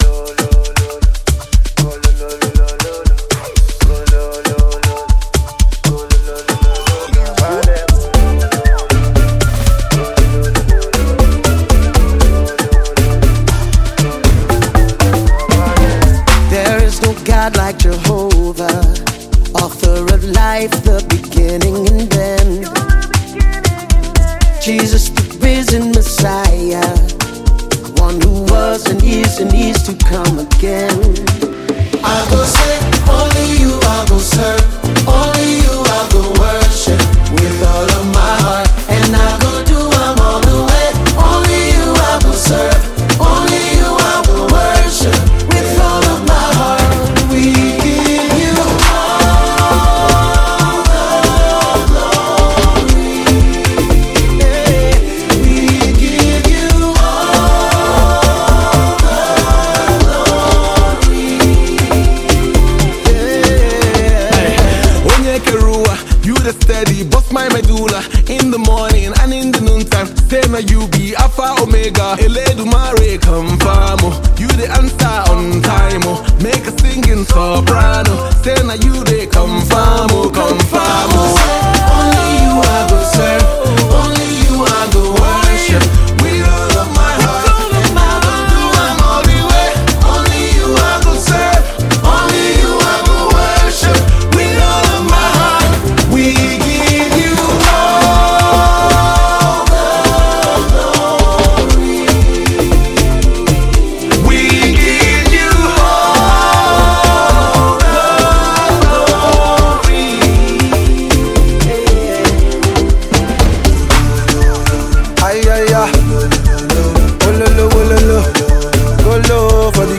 Download Latest Gospel Songs
American gospel artist